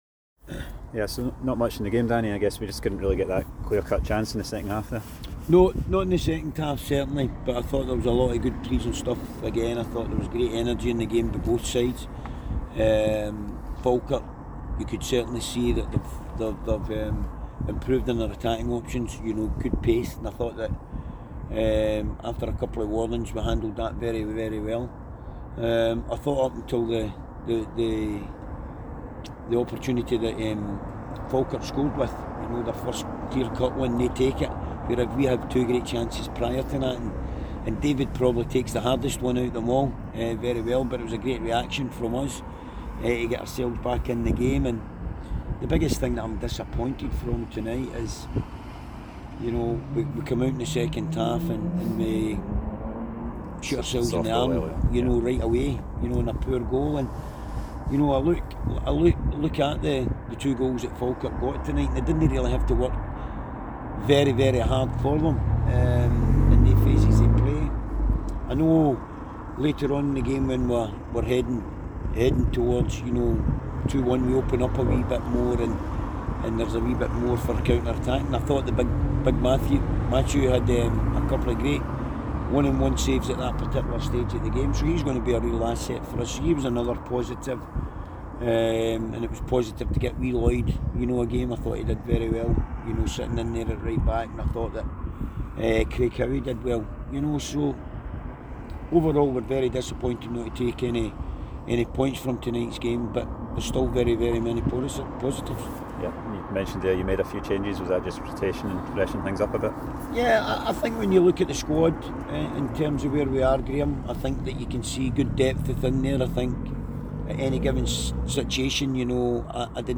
press conference after the Betfred Cup match.